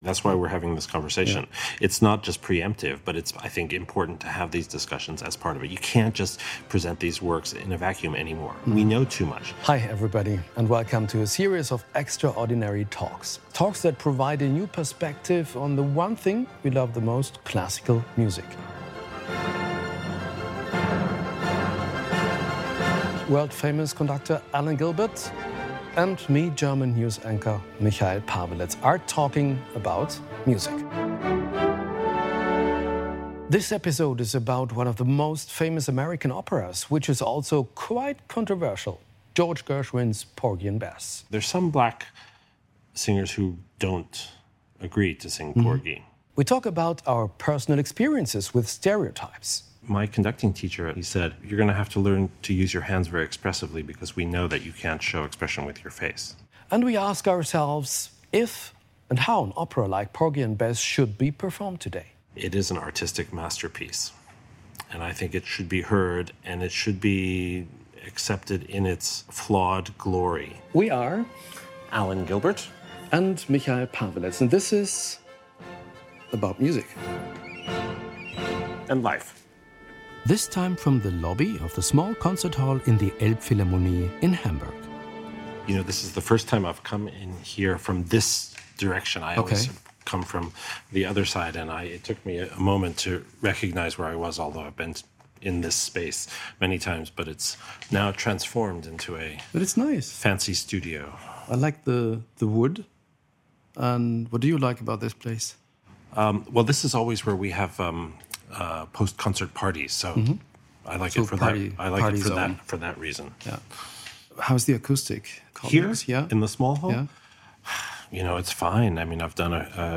Anstoß zur Diskussion oder Verbreitung rassistischer Stereotype? Warum Gershwins Oper "Porgy & Bess" in einen Kontext gestellt werden muss - darüber diskutieren Dirigent Alan Gilbert und Tagesschau-Moderator Michail Paweletz im NDR Podcast "about music".